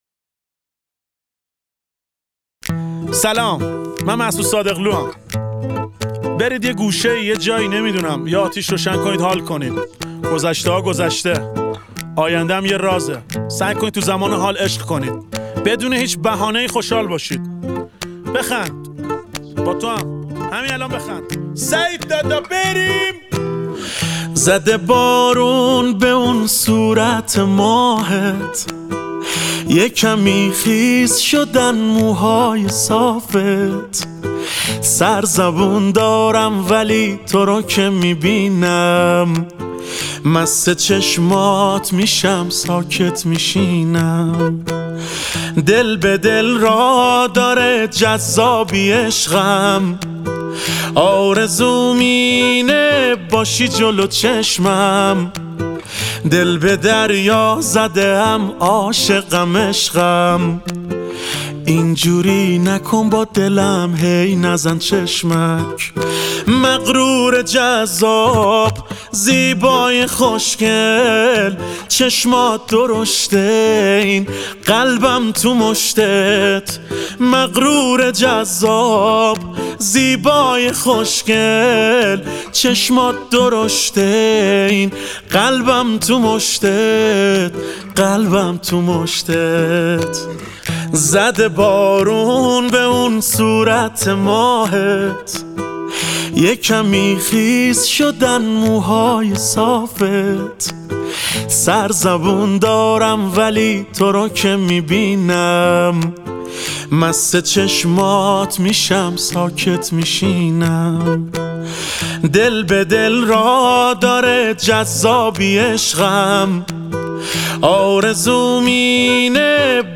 تک اهنگ ایرانی
گیتار